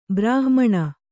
♪ brāhmaṇa